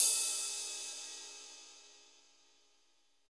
CYM RIDE40BR.wav